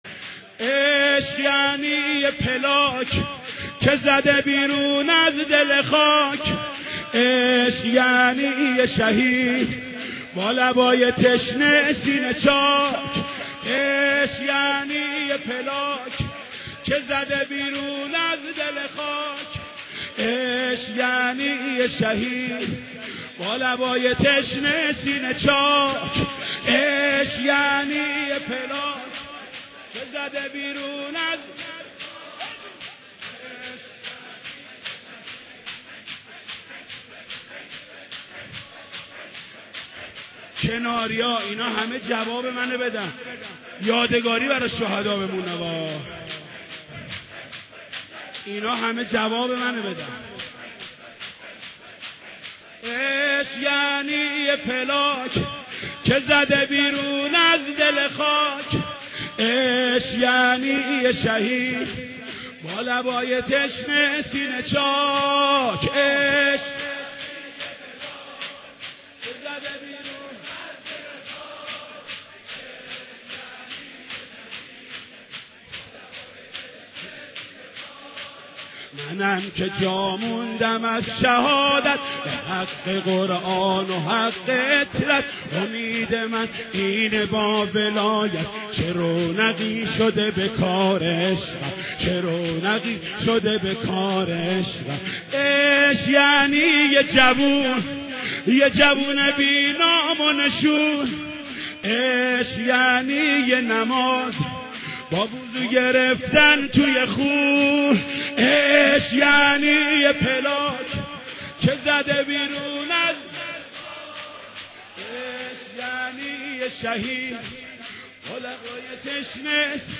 ویژه گرامیداشت هفته دفاع مقدس؛